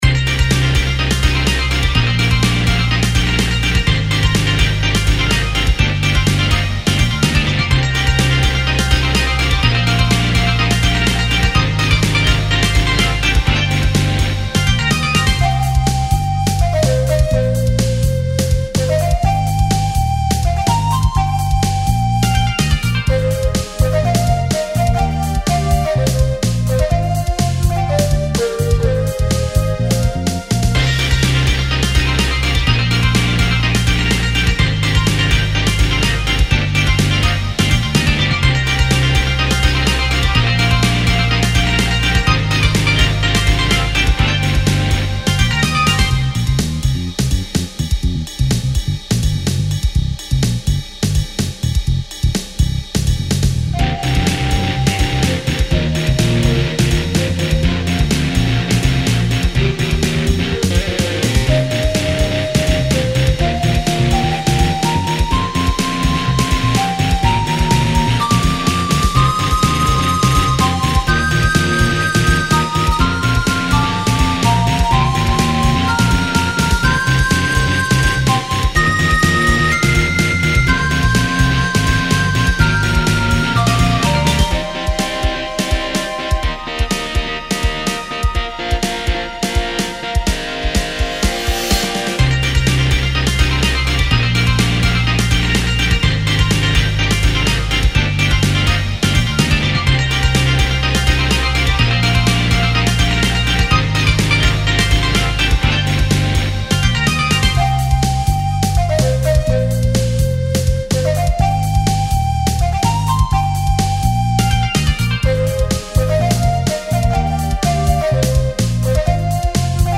ロックロング激しい